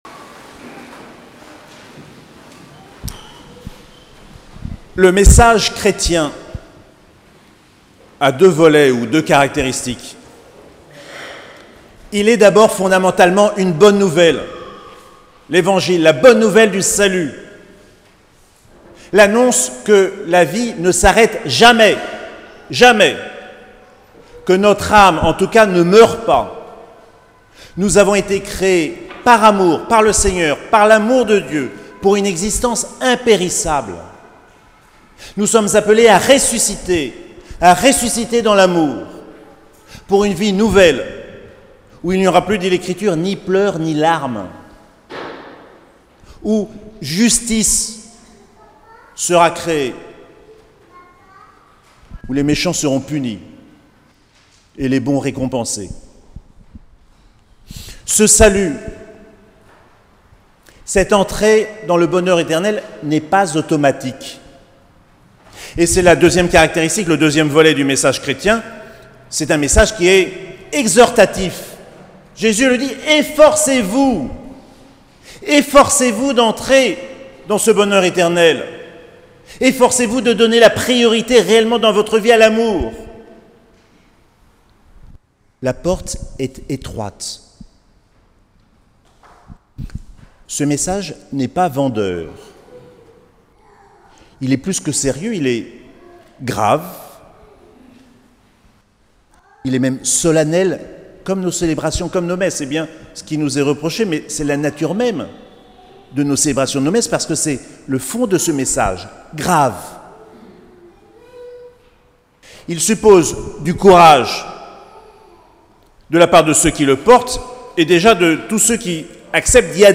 21ème dimanche du Temps Ordinaire - 25 août 2019